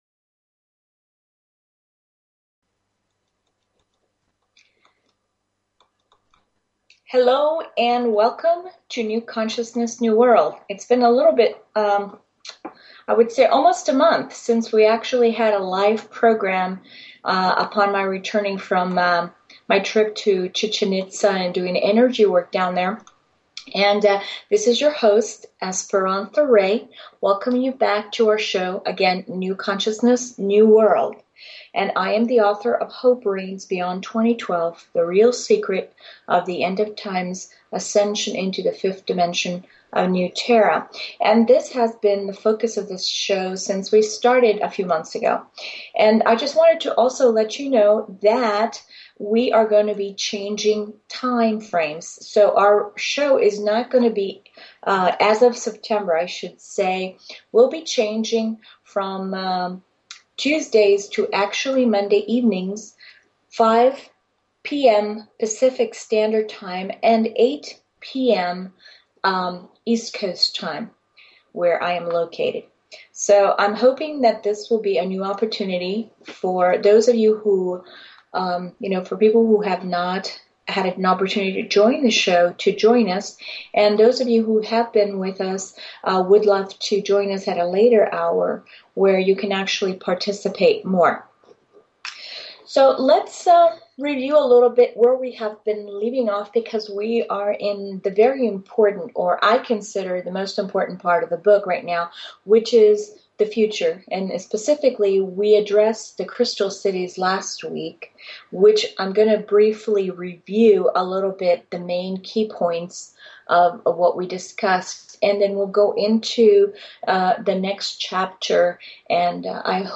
Talk Show Episode, Audio Podcast, New_Consciousness_New_World and Courtesy of BBS Radio on , show guests , about , categorized as
This will be accomplished through a series of discussion, interviews with other experts, teaching techniques to help in this process, and audience participation through questions and answers.